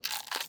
Minecraft Version Minecraft Version snapshot Latest Release | Latest Snapshot snapshot / assets / minecraft / sounds / mob / panda / eat1.ogg Compare With Compare With Latest Release | Latest Snapshot
eat1.ogg